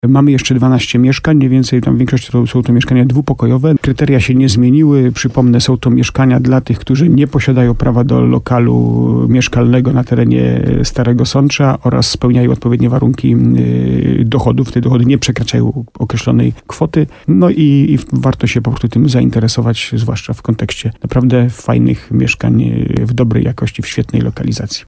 Jeszcze niezdecydowanych zaprasza do skorzystania z oferty burmistrz Starego Sącza Jacek Lelek.